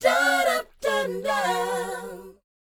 DOWOP C#FU.wav